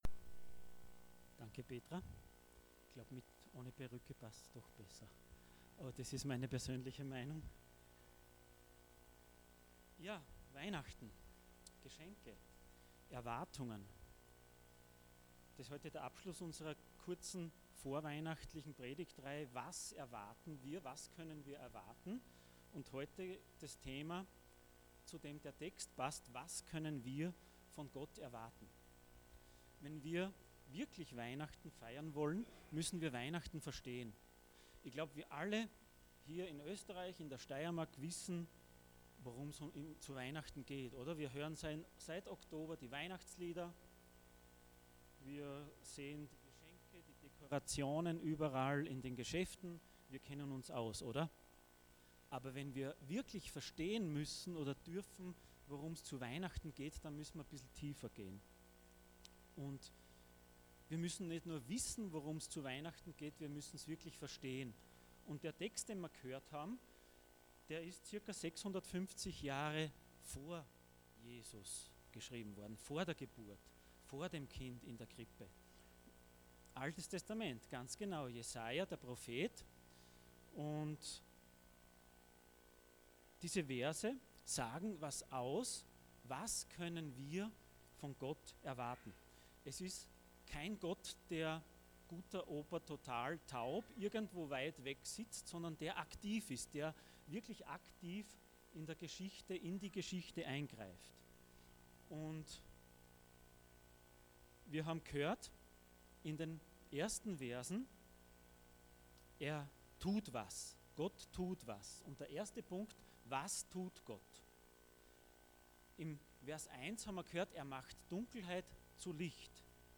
In Erwartung Passage: Isaiah 9:1-6 Dienstart: Weihnachten %todo_render% Was wir von Gott erwarten können « Was erwartet Gott von mir?